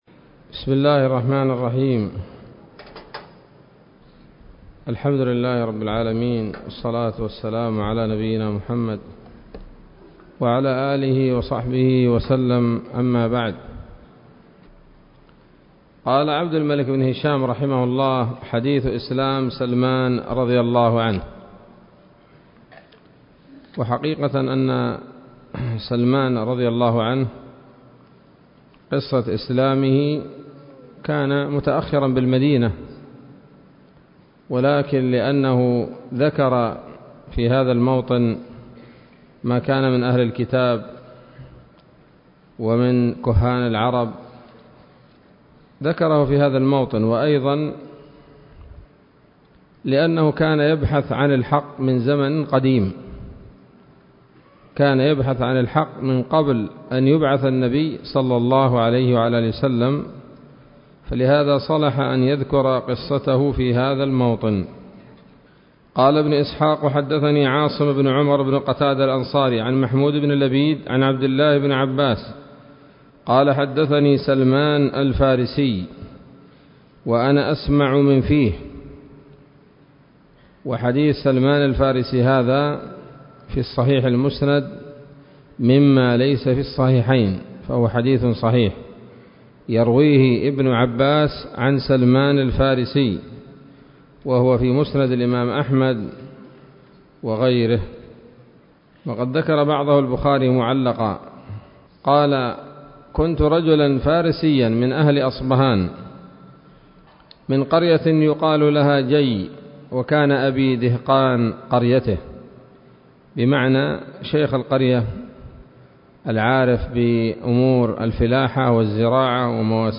الدرس التاسع عشر من التعليق على كتاب السيرة النبوية لابن هشام